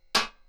hitMetal1.wav